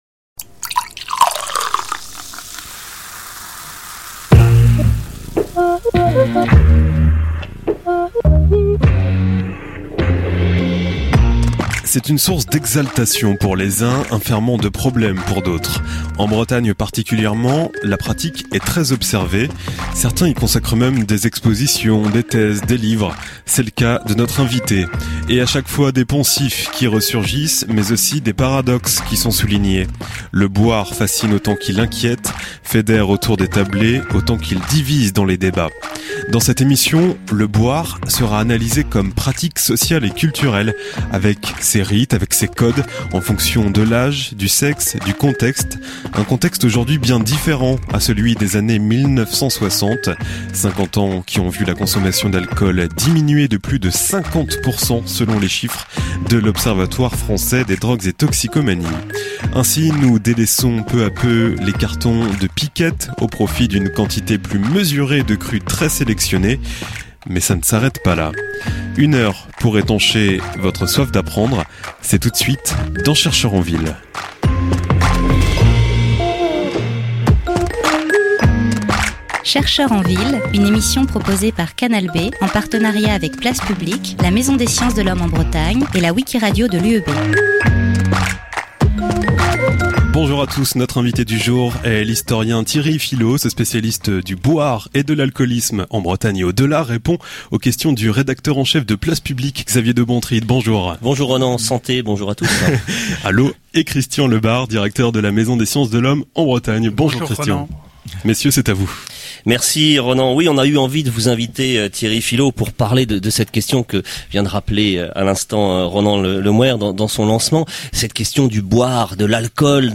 Le débat a ensuite exploré plus précisément le boire jeune et le boire au féminin. Acte social, acte culturel, cette pratique évolue en fonction du sexe et de l'âge, du genre et des générations comme le souligne l'ouvrage collectif auquel il vient de participer aux Presses de l'EHESP !